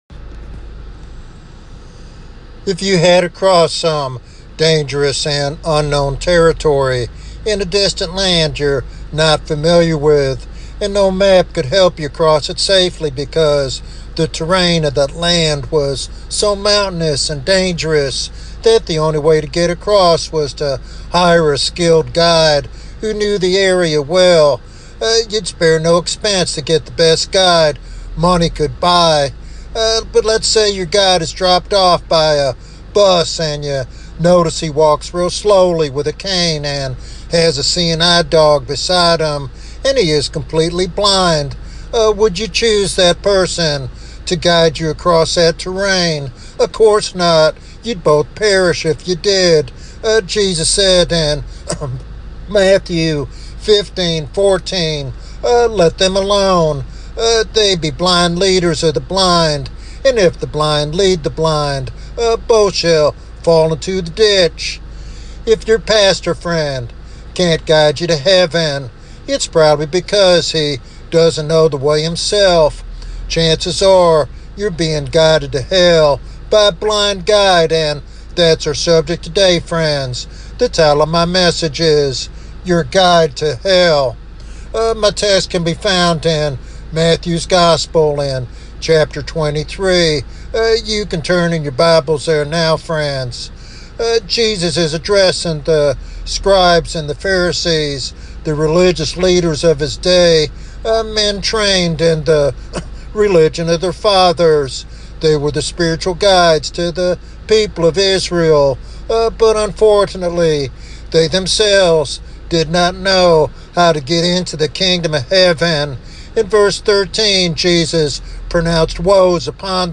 This sermon is a call to vigilance and spiritual discernment in the journey toward heaven.